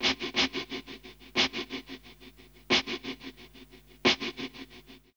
Index of /90_sSampleCDs/USB Soundscan vol.34 - Burning Grunge Hip Hop [AKAI] 1CD/Partition E/04-3HHM 89